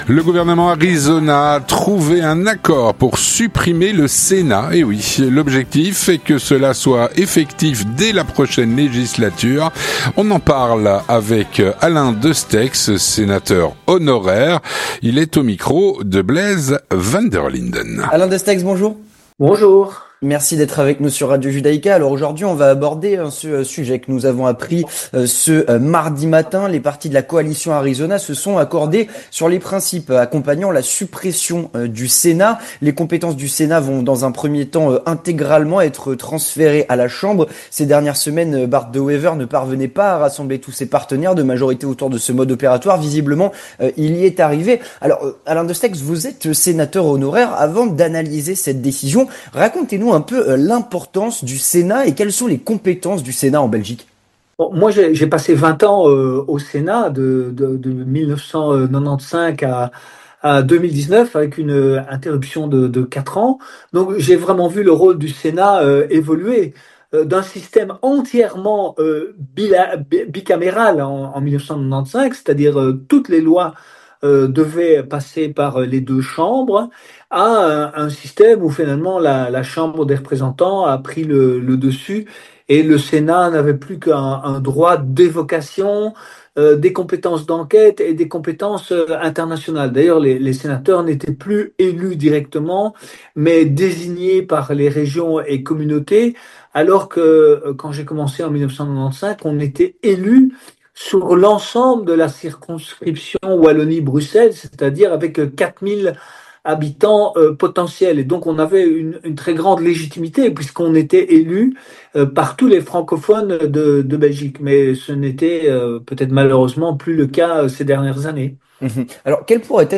On en parle avec Alain Destexhe, sénateur honoraire.